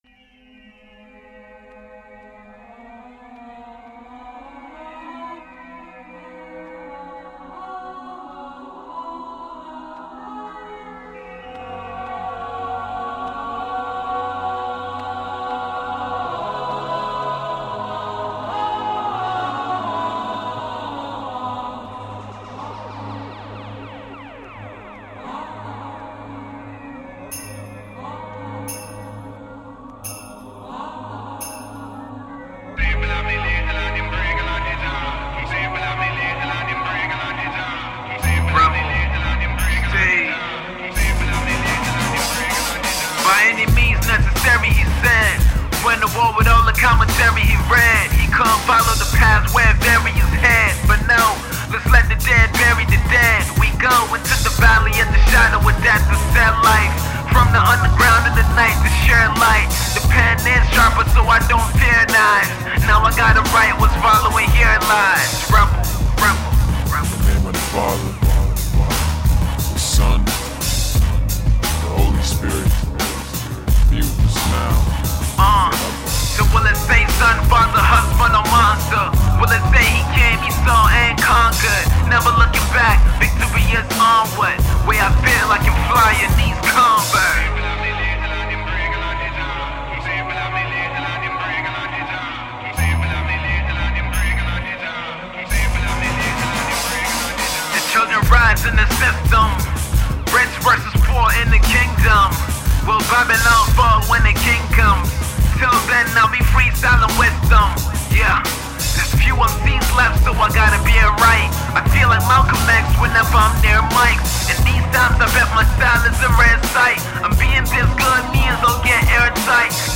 all the while staying true to its core hip-hop roots.